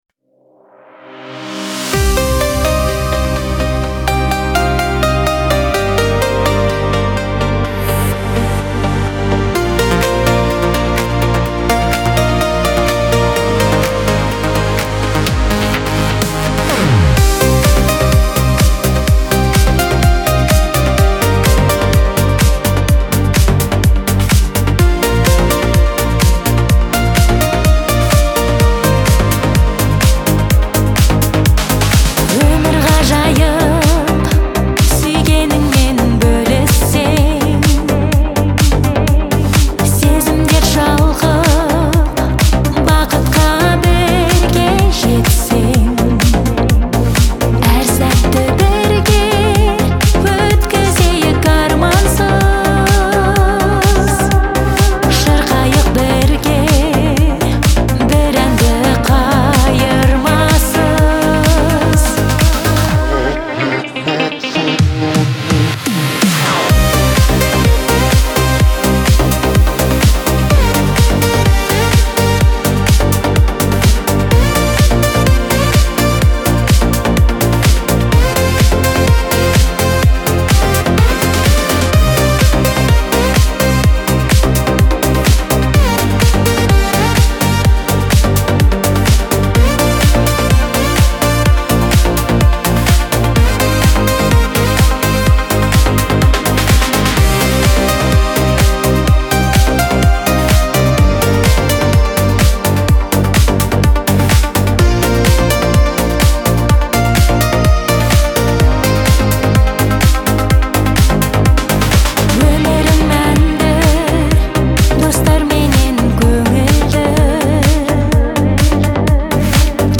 Грустные
Трек размещён в разделе Казахская музыка.